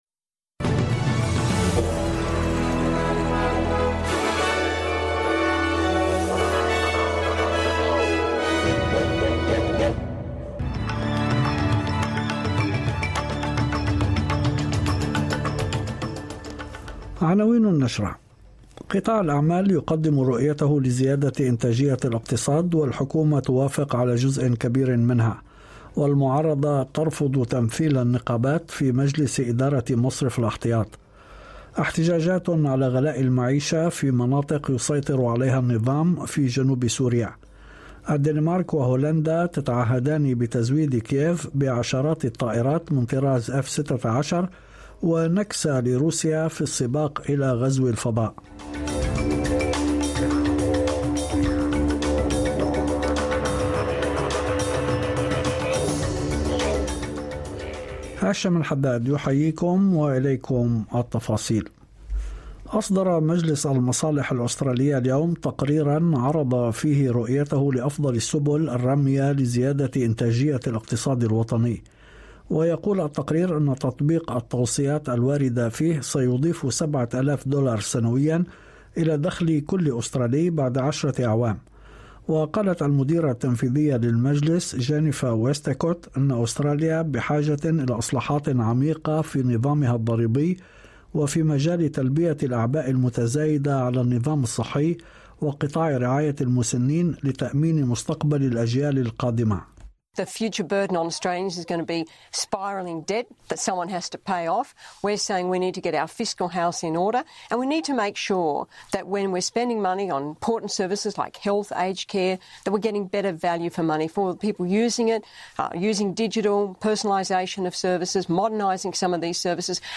نشرة أخبار المساء 21/8/2023